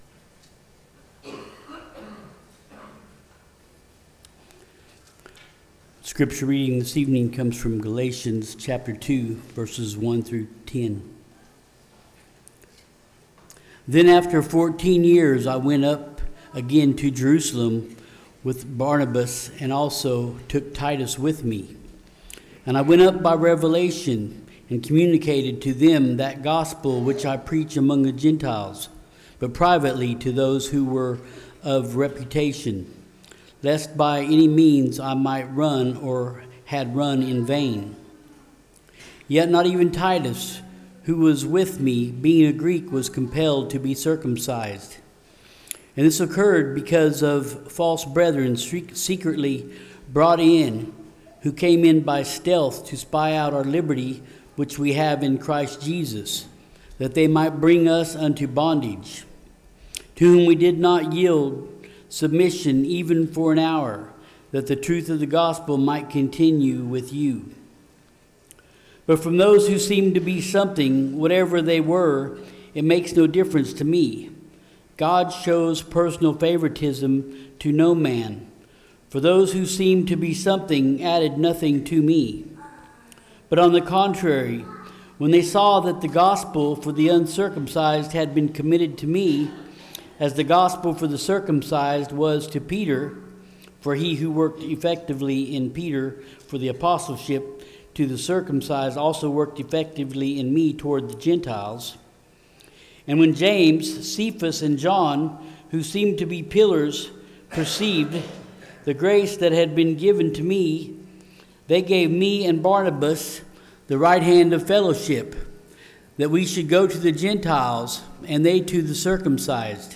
2025 Passage: Galatians 2:1-10 Service Type: Sunday PM Topics